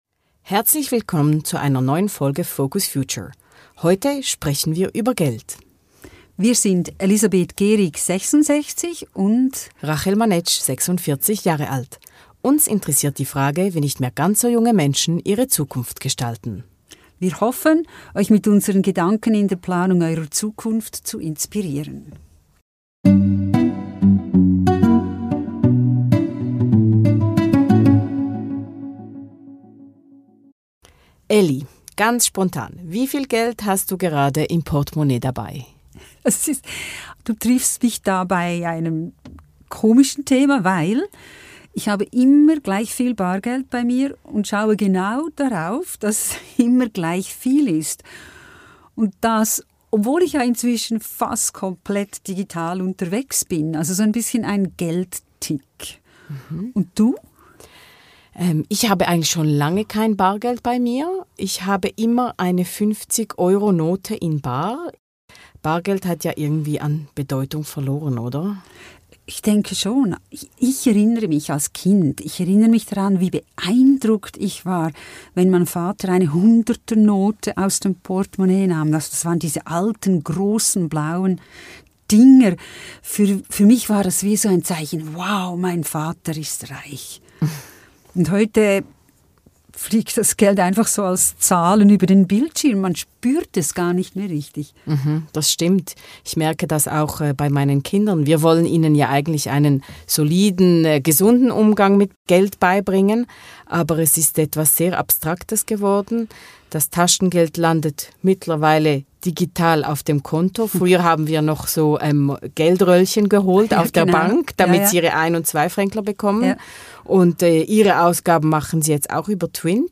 Hört rein in ein Gespräch über das schwierige Thema Geld!